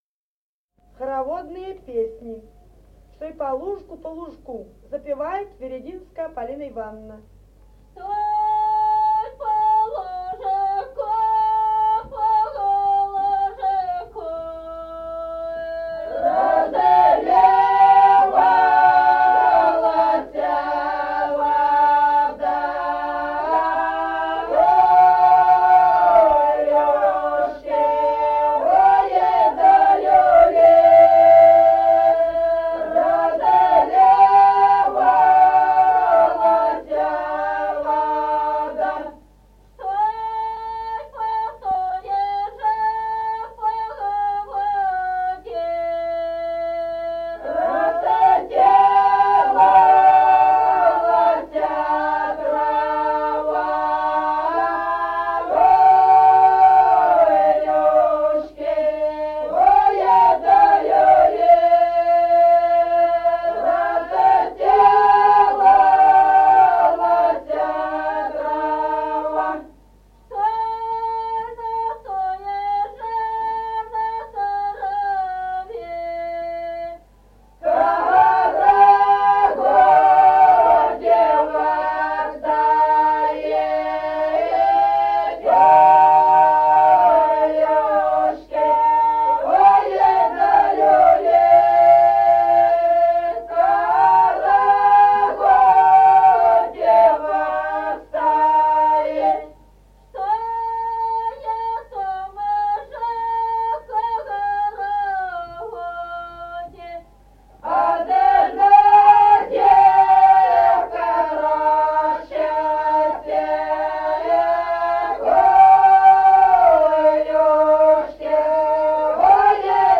Песни села Остроглядово. Чтой по лужку.